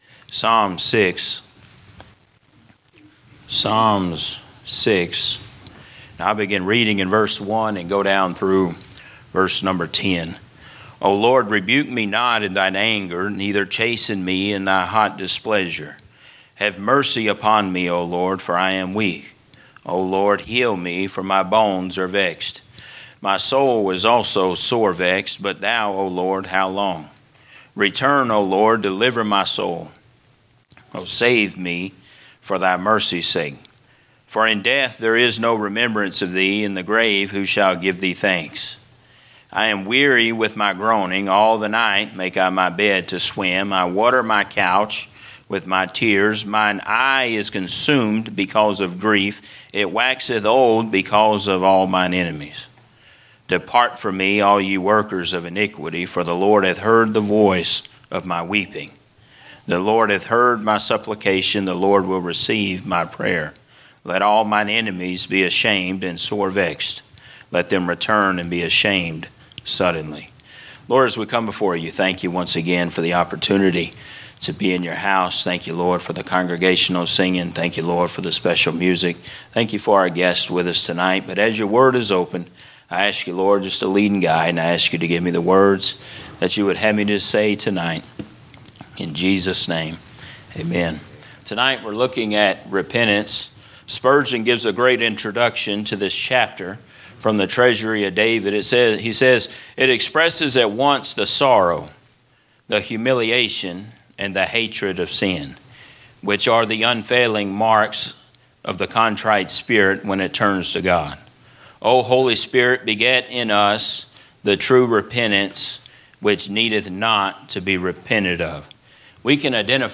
From Series: "General Preaching"